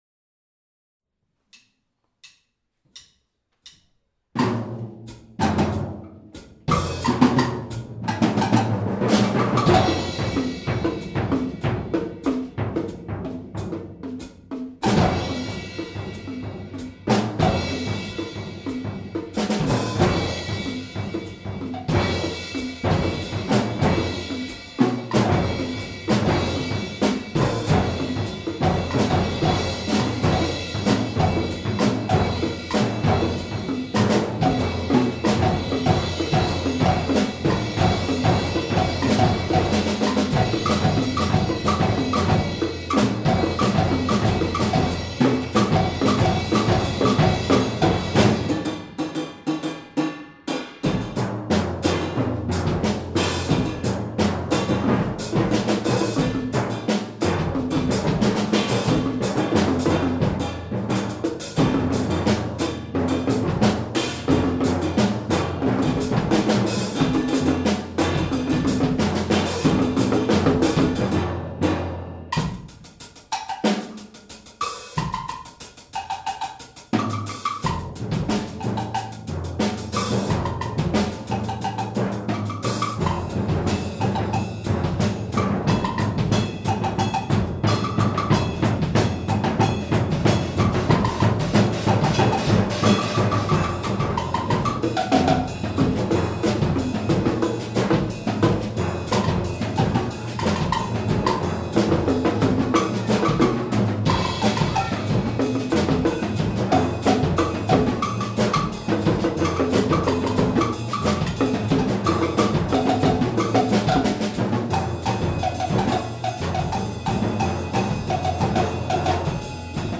Genre: Percussion Ensemble
Player 2 (bongos, kick bass drum laid flat)
Player 3 (2 timpani [32" & 29"], trash cymbal place on wood)
Player 4 (drum set, 2 cowbells)